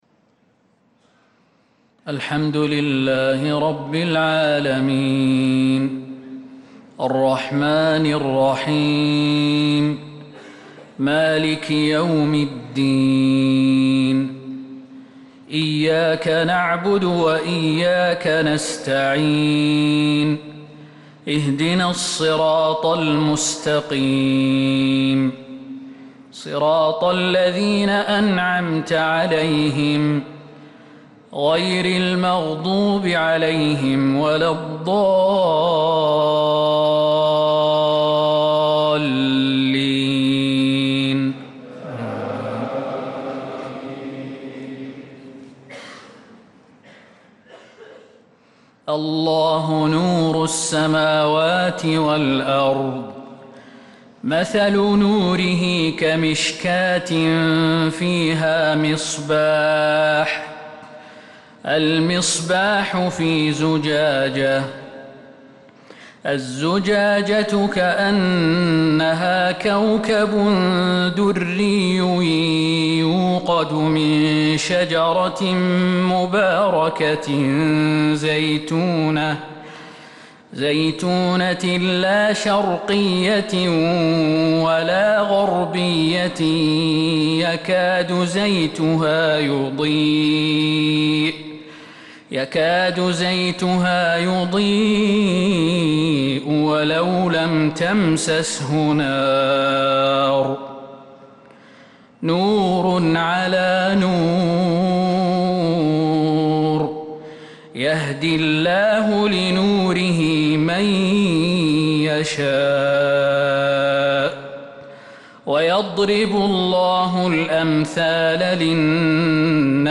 فجر الأحد 11 محرم 1447هـ من سورة النور 35-46 | Fajr prayer from Surah An-Nur 6-7-2025 > 1447 🕌 > الفروض - تلاوات الحرمين